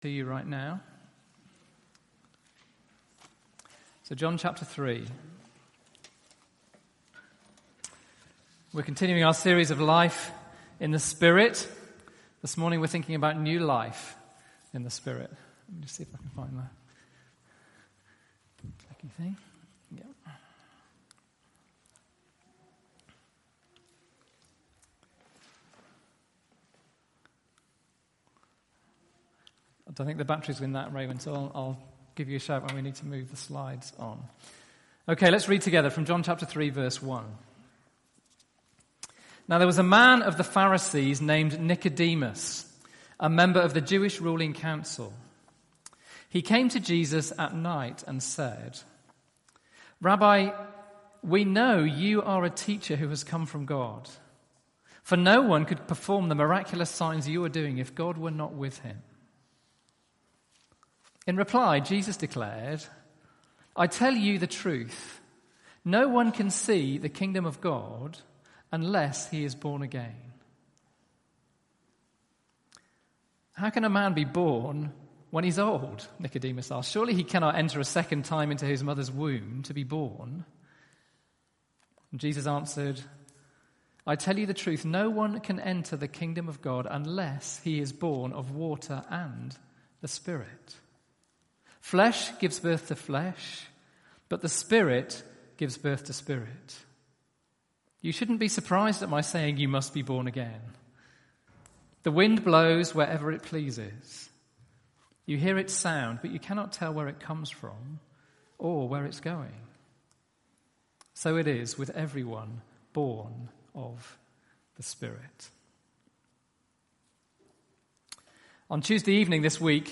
Sunday Service
2: New Life; Conversion Sermon